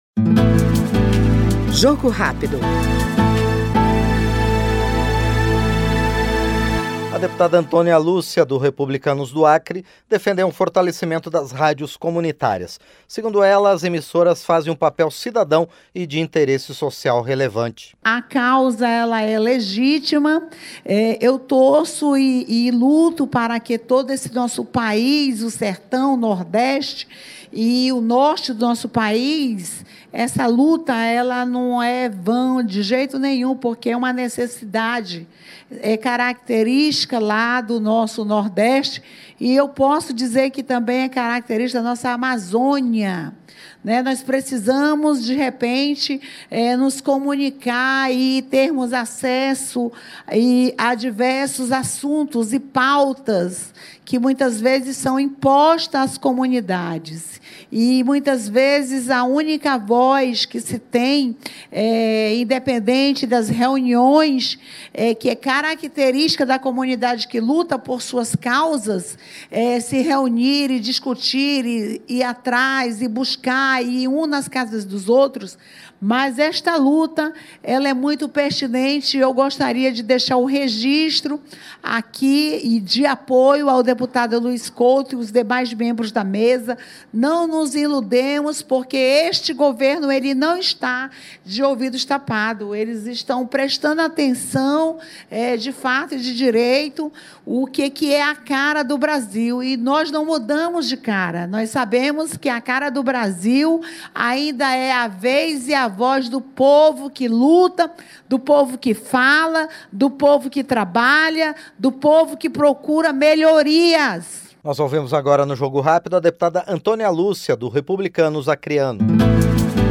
Jogo Rápido é o programa de entrevistas em que o parlamentar expõe seus projetos, sua atuação parlamentar e sua opinião sobre os temas em discussão na Câmara dos Deputados.